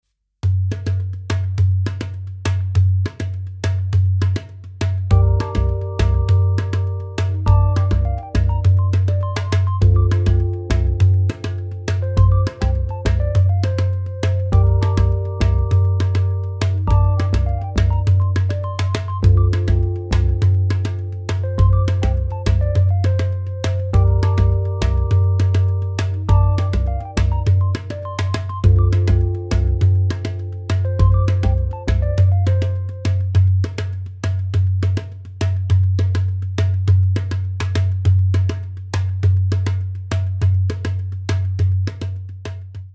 MEINL Percussion Travel Series African Djembe 10"- Kenyan Quilt (PADJ2-M-G)